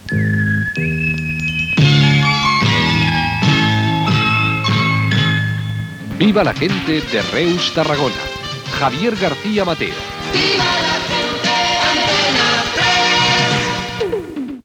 Sintonia de la cadena i indicatiu de la desconnexió local.